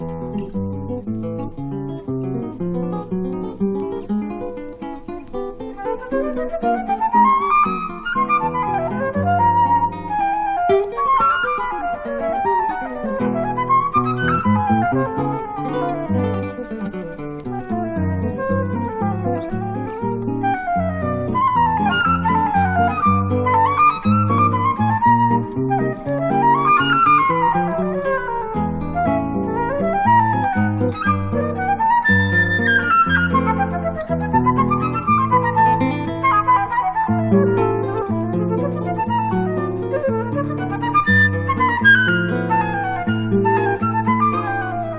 片面ずつに「クラシック」「モダン」と銘打って、ギターとヴィオラとフルートという編成で。
Classical　USA　12inchレコード　33rpm　Mono